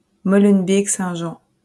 몰뢴베크생장(프랑스어: Molenbeek-Saint-Jean, 발음: [molœnbek sɛ̃ ʒɑ̃, -bɛk -] (